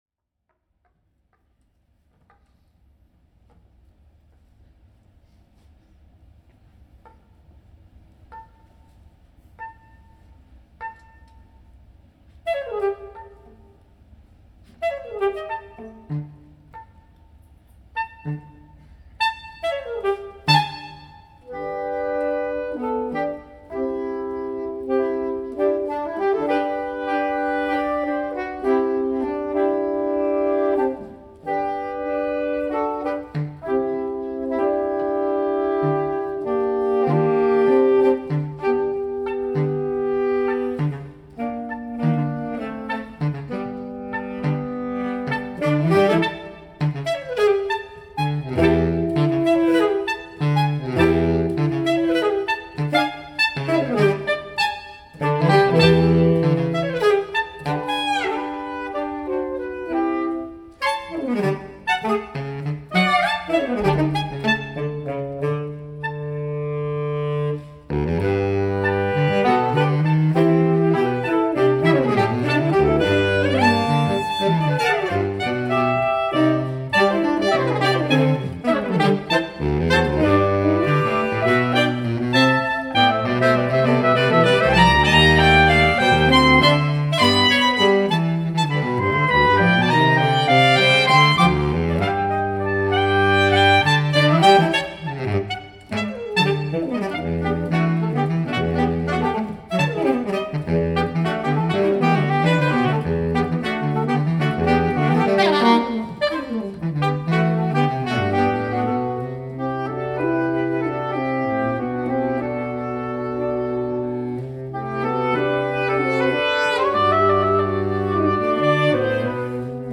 musique de chambre /chamber music